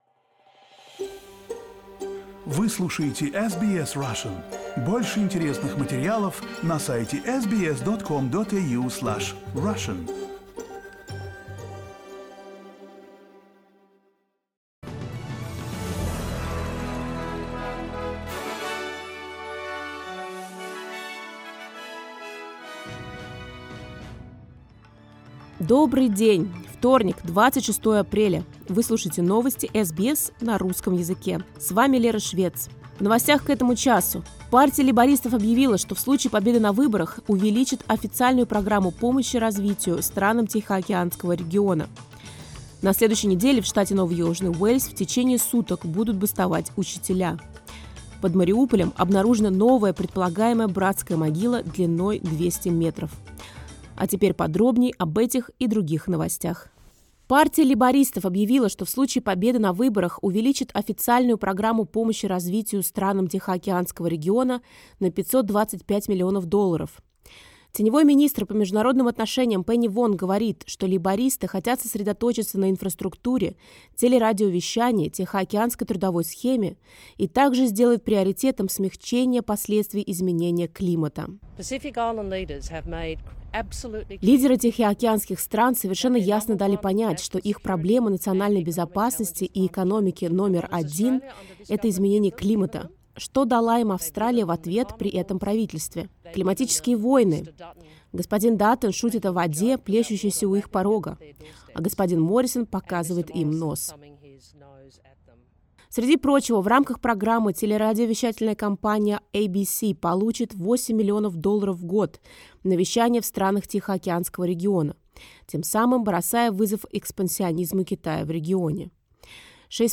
Listen to the latest news headlines in Australia from SBS Russian